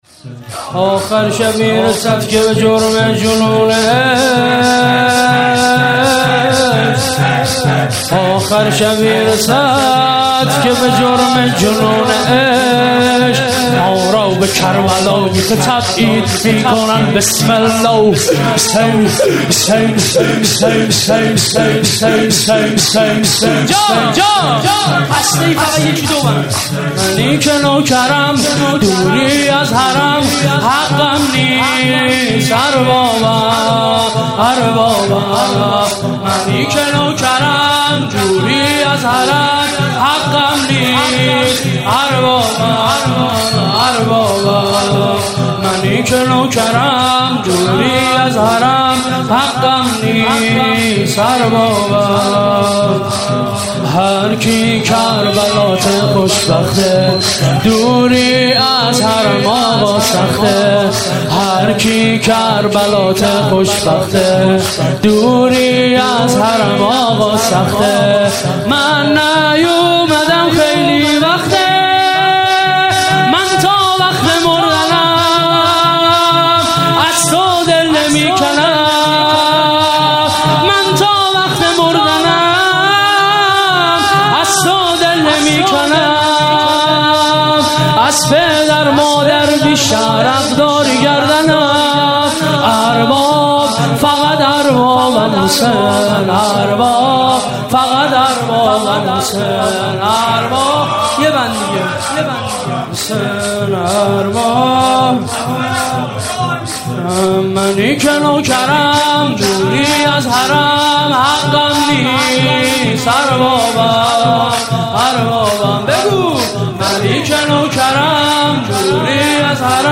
شب نوزدم ماه مبارک رمضان